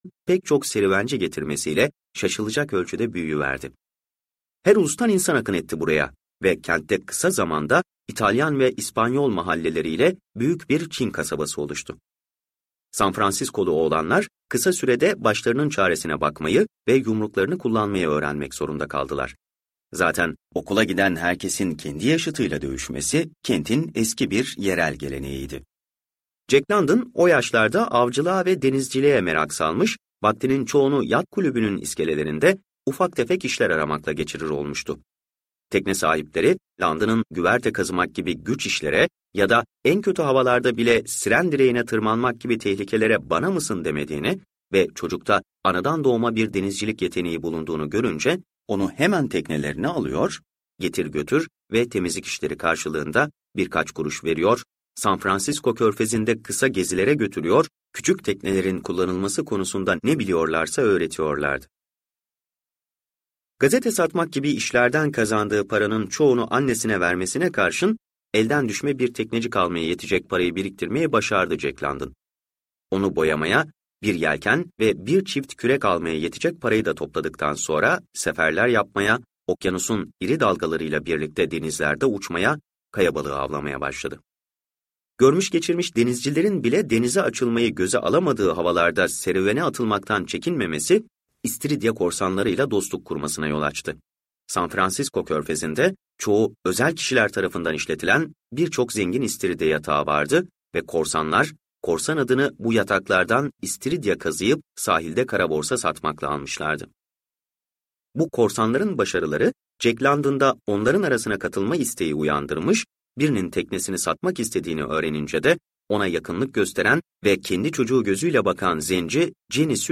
Denizin Çağrısı - Seslenen Kitap